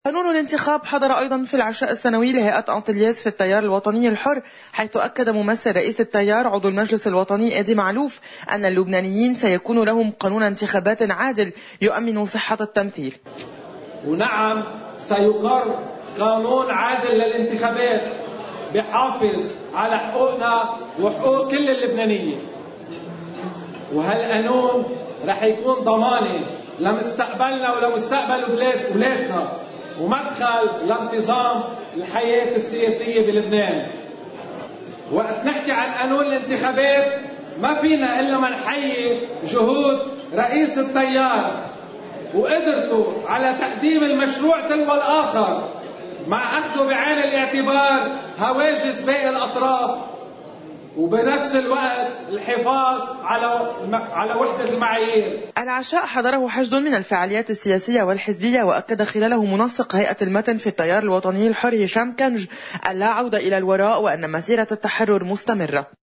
العشاء حضره حشد من الفعاليات السياسية والحزبية.